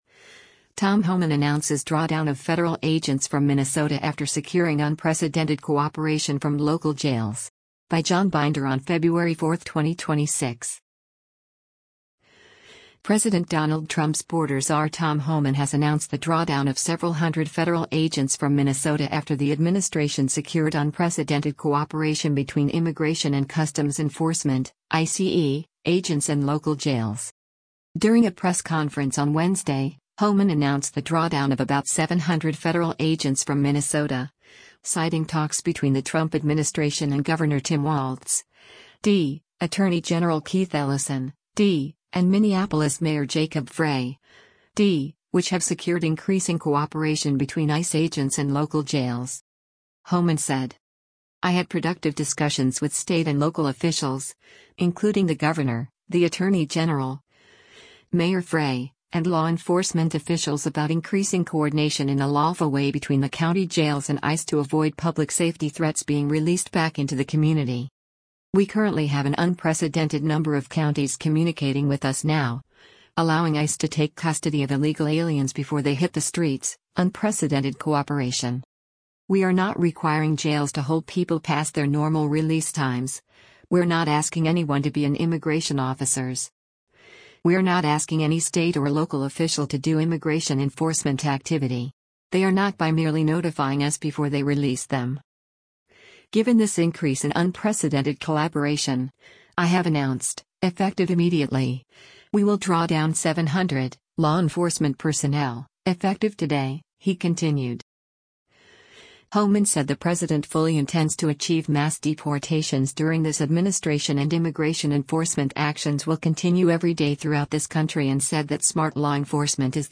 During a press conference on Wednesday, Homan announced the drawdown of about 700 federal agents from Minnesota — citing talks between the Trump administration and Gov. Tim Walz (D), Attorney General Keith Ellison (D), and Minneapolis Mayor Jacob Frey (D), which have secured increasing cooperation between ICE agents and local jails.